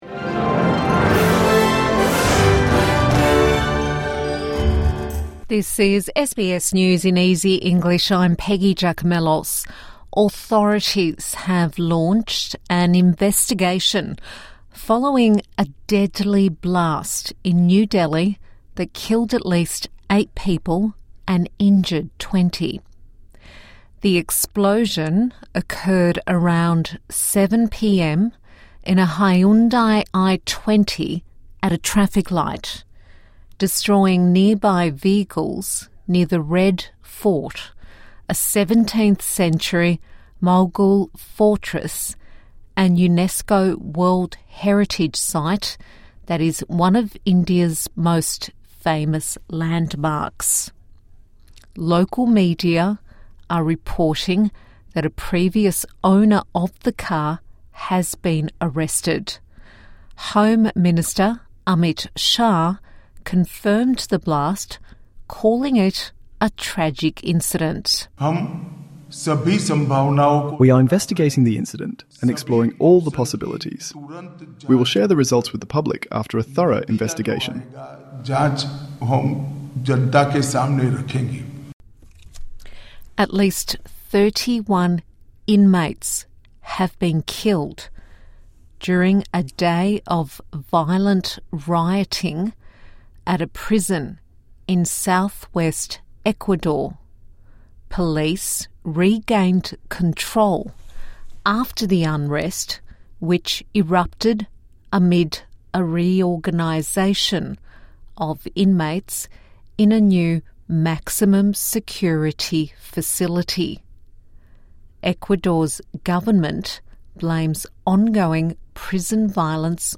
A five minute bulletin for English Language learners